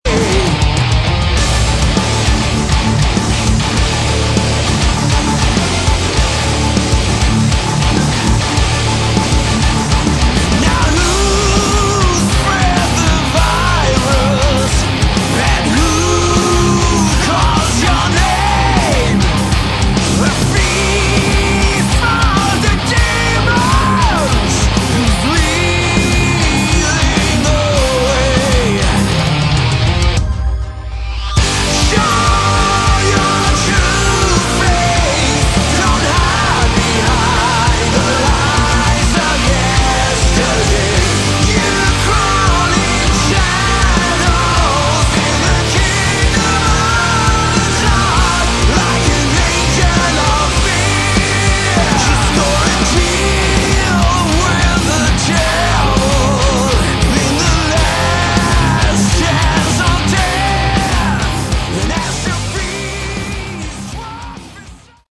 Category: Melodic Metal
vocals
guitars
bass
drums
keyboards